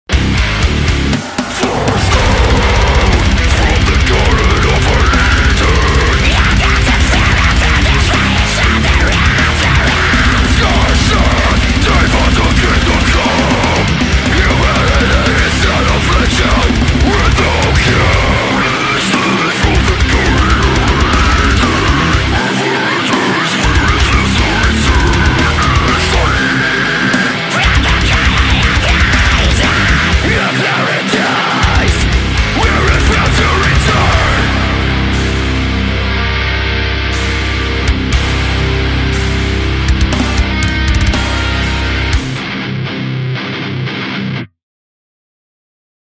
Dengan racikan metalcore/deathcore penuh intensitas